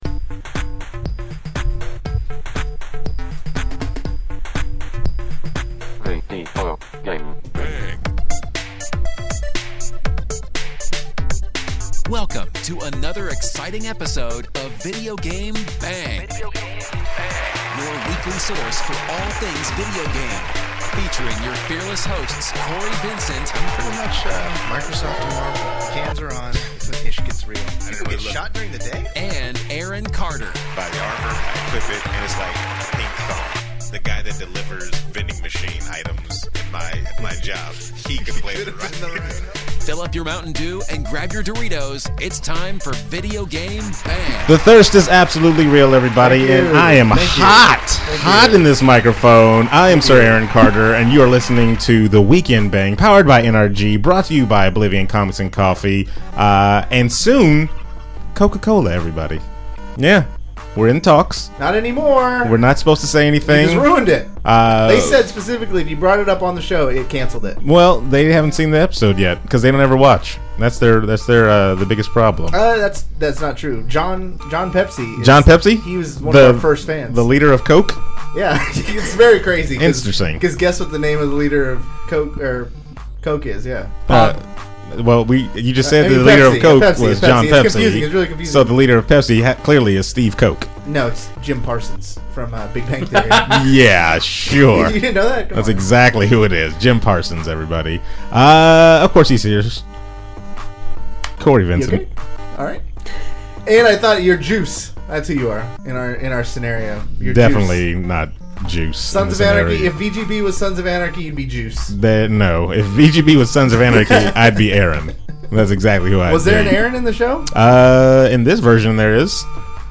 The Weekend BANG is taking the Marvel world by storm and with a new adventure role playing session.